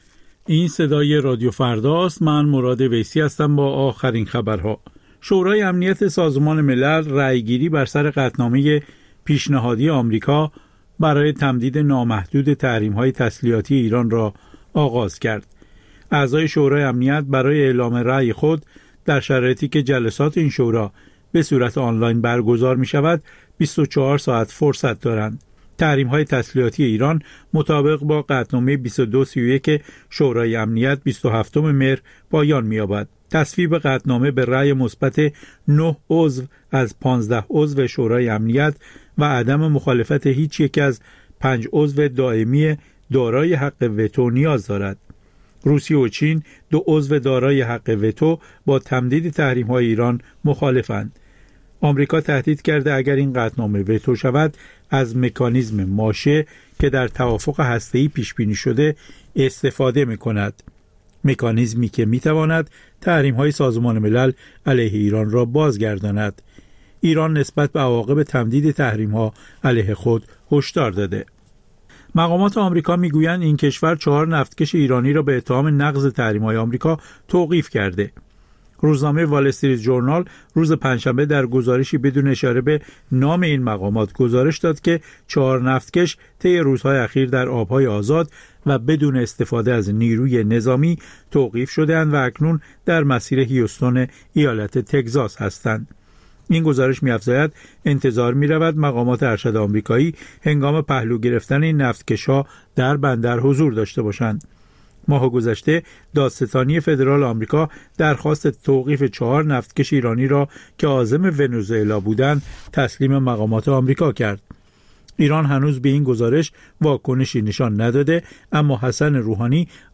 اخبار رادیو فردا، ساعت ۸:۰۰
پخش زنده - پخش رادیویی